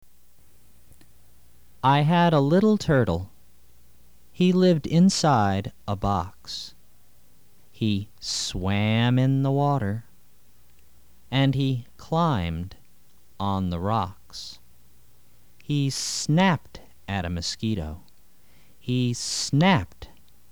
Action Song for Young Children